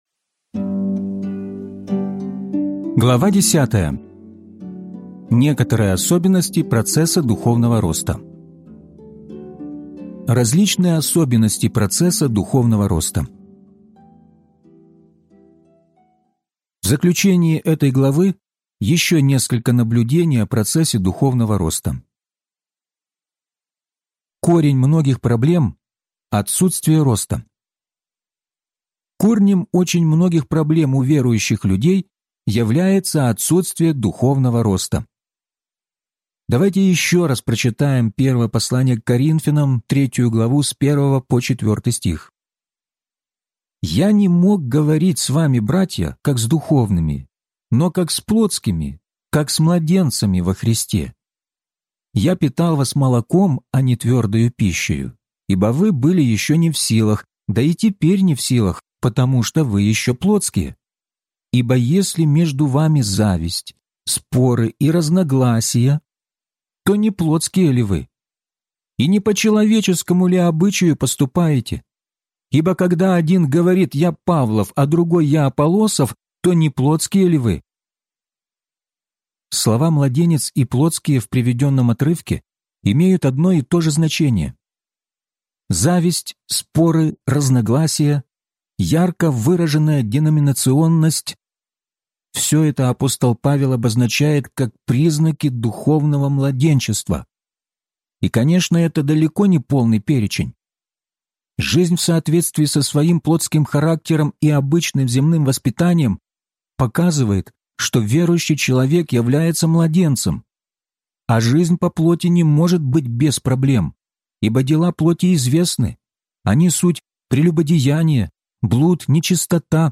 Возрастайте! (аудиокнига) - День 30 из 34